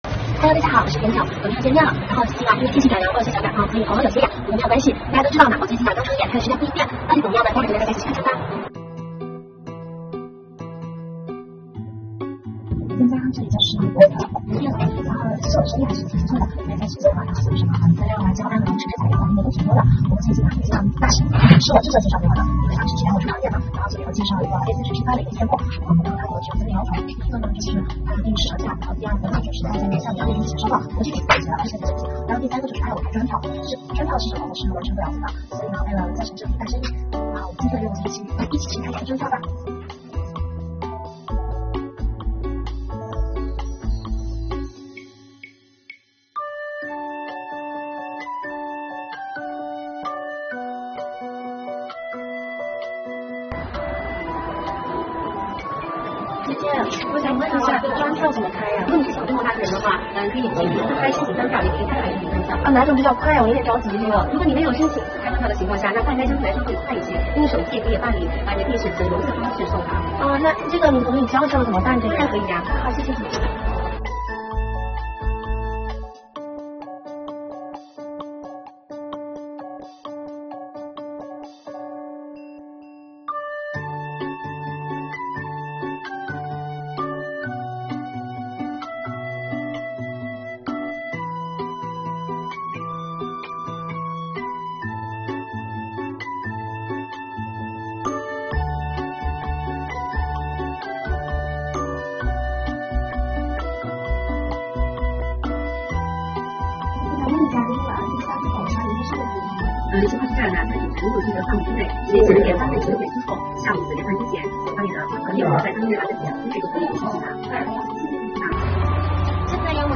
Vlog丨创业大学生带你学习如何代开专票，so easy！